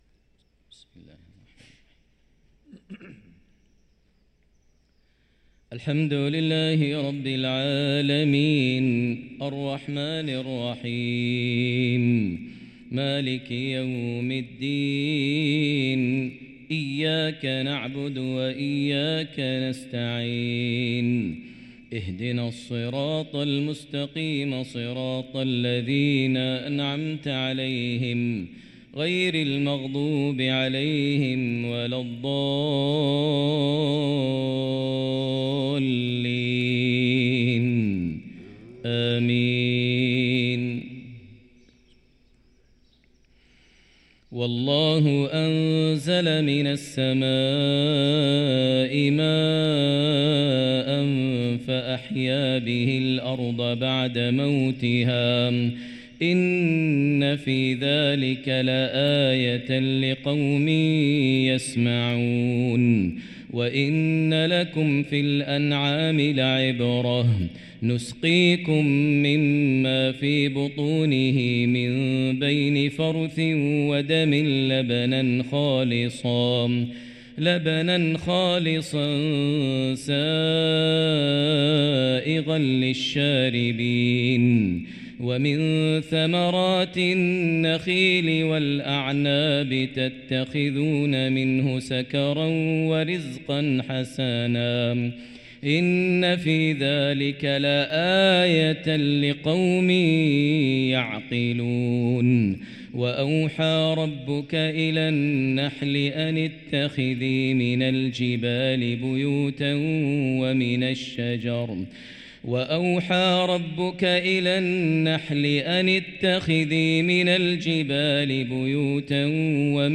صلاة الفجر للقارئ ماهر المعيقلي 17 رجب 1445 هـ
تِلَاوَات الْحَرَمَيْن .